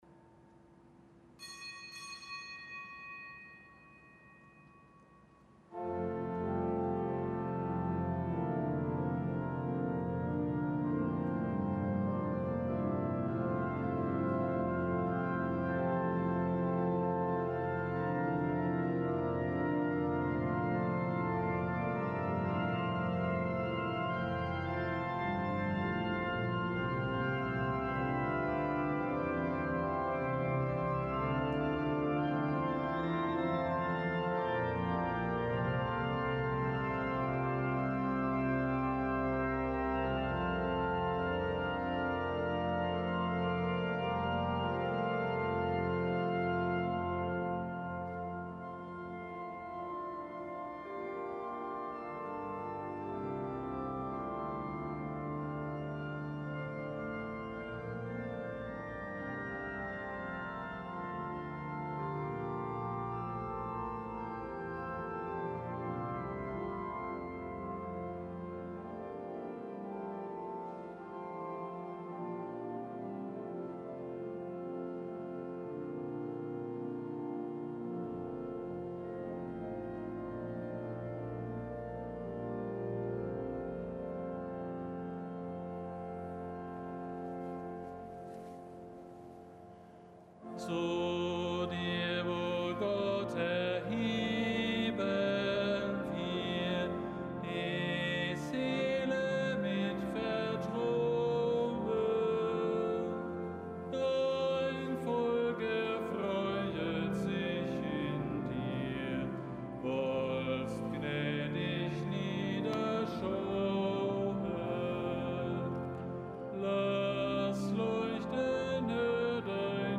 Gottesdienst
Heilige Messe aus dem Kölner Dom am Mittwoch der 9. Woche im Jahreskreis. Nichtgebotener Gedenktag Heiliger Marcellinus und heiliger Petrus, Märtyrer in Rom.